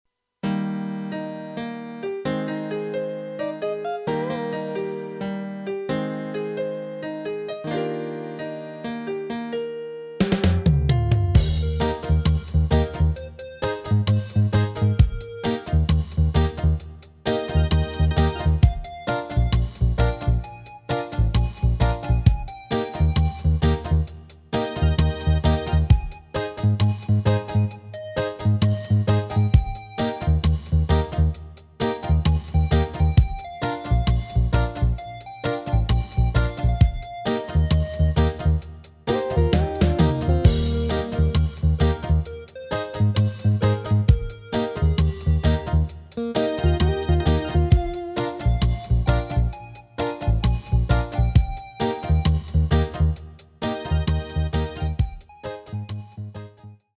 Rubrika: Pop, rock, beat
Karaoke
Nejnovější MP3 podklady